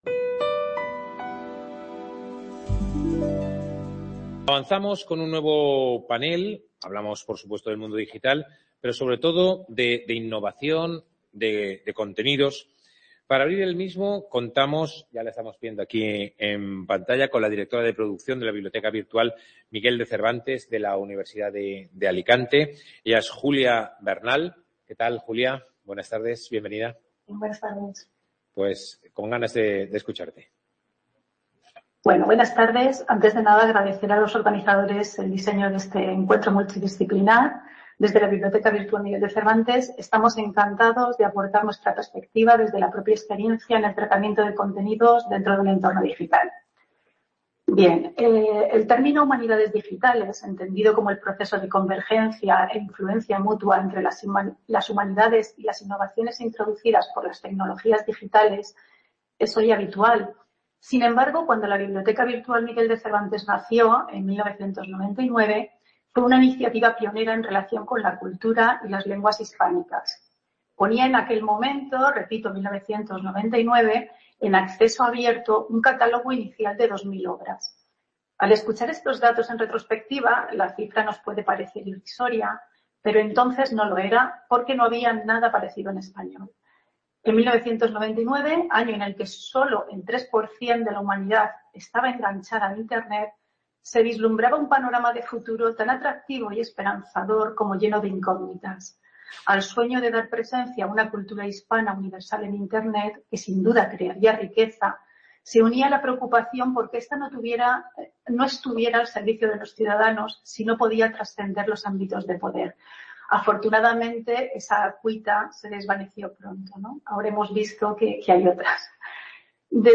+CREACION+EDUCACIÓN+COMUNICACIÓN+TECNOLOGÍA +INTELIGENCIA ARTIFICIAL+DERECHOS CONVERSACIONES INSPIRACIONALES.